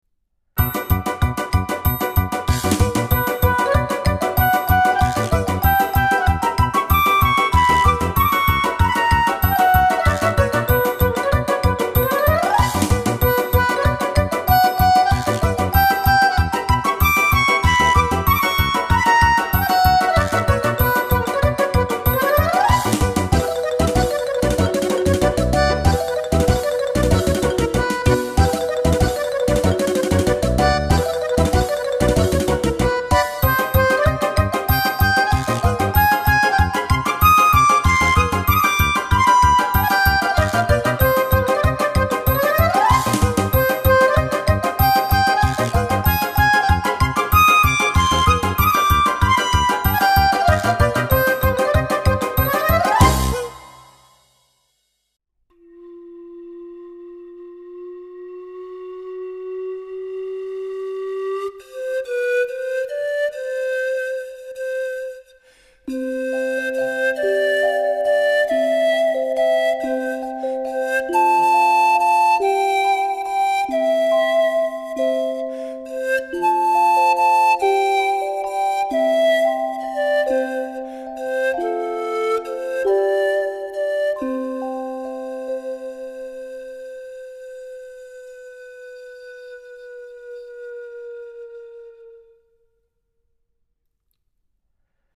muziek van rat: trippelen in kleine stapjes